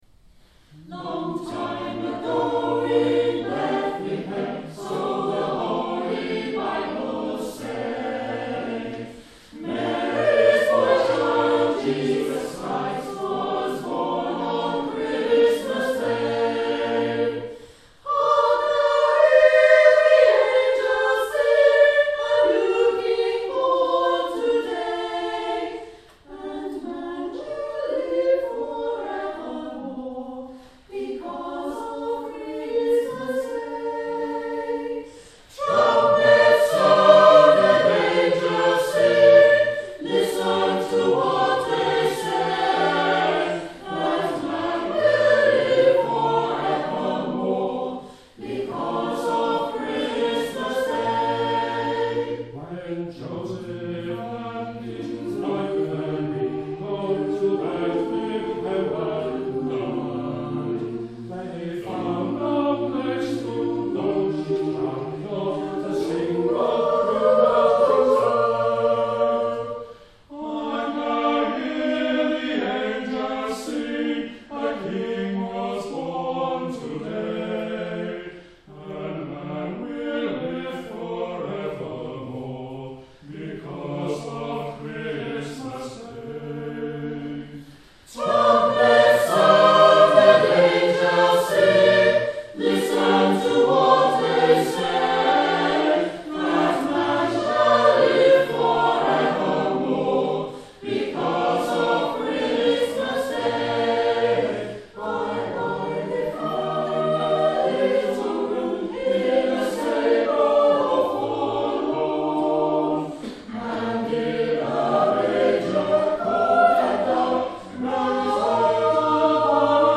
I'm part of a brand new vocal group of 12 singers and we had our first concert tonight (after only 2 rehearsals together).